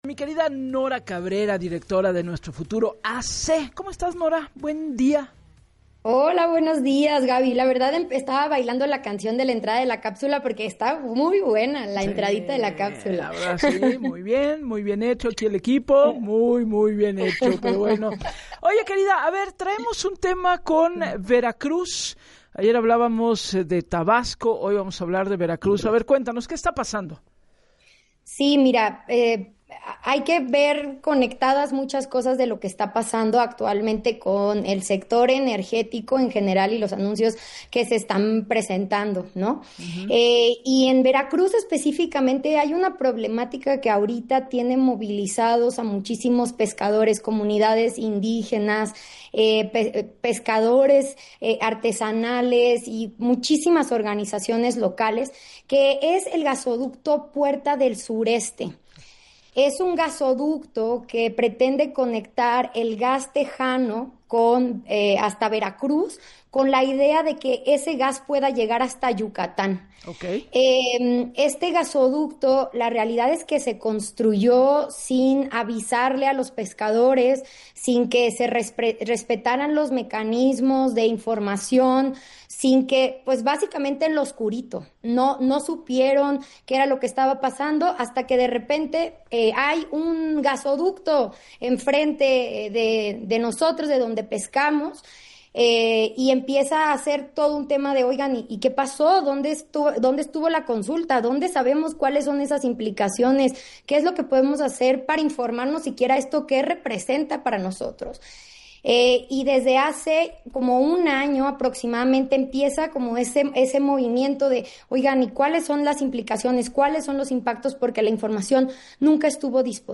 EN VIVO